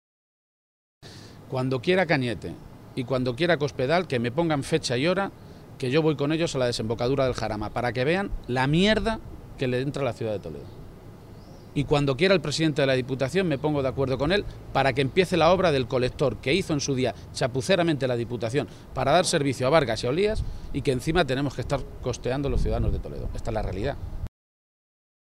Emiliano García-Page, Secretario General del PSOE de Castilla-La Mancha
Cortes de audio de la rueda de prensa